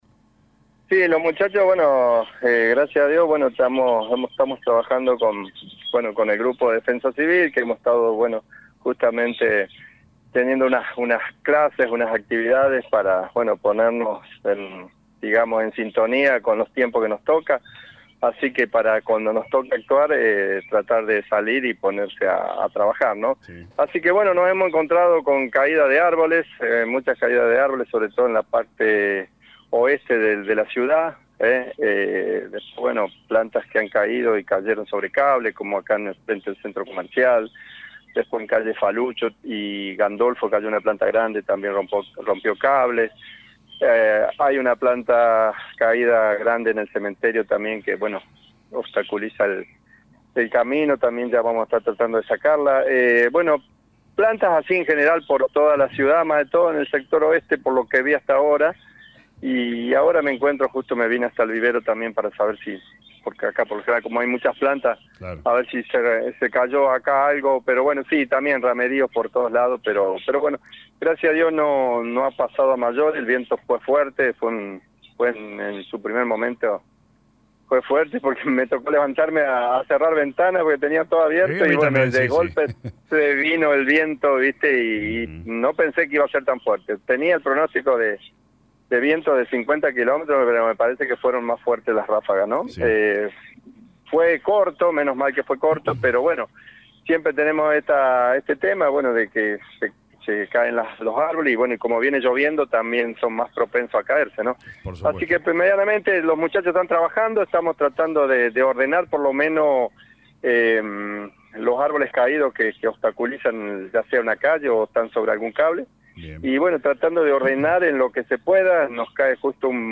En diálogo con LA RADIO 102.9 FM el secretario de Servicios Públicos y Ambiente Walter Olivera dijo que desde temprano cuadrillas de empleados municipales realizan un relevamiento por distintos sectores de Brinkmann y si bien el temporal fue muy fuerte no hubo mayores complicaciones.